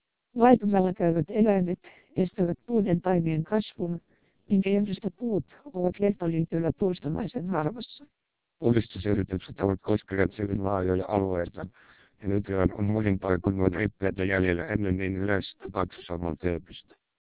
Speech Samples (WAV-files).
Although the opinions of the listeners shifted in this case, the majority still favored the TWELP vocoder.